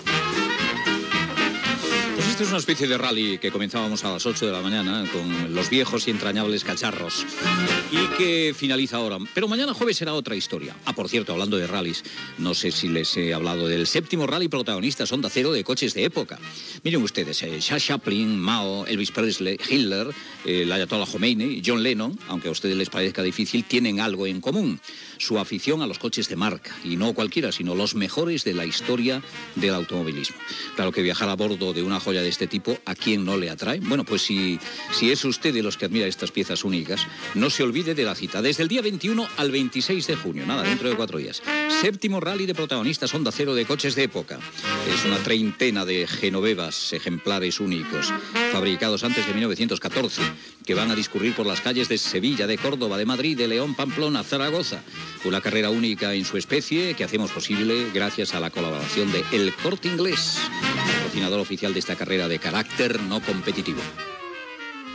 Info-entreteniment